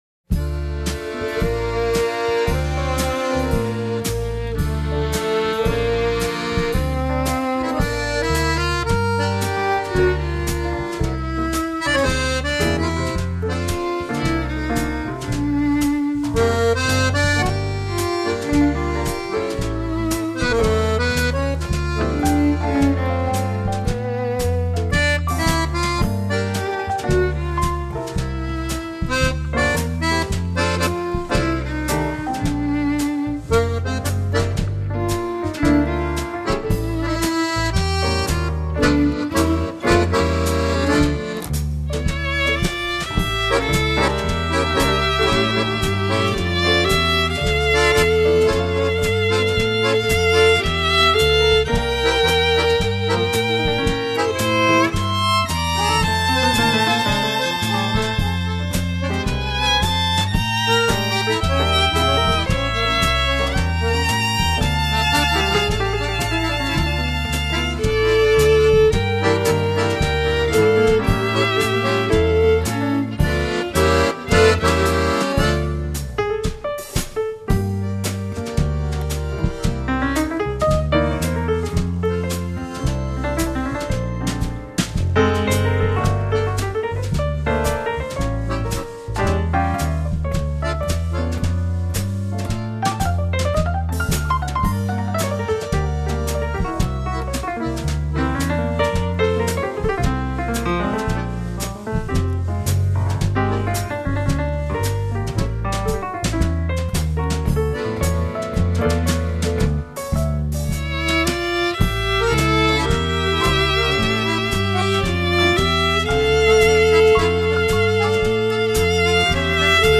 09 Foxtrot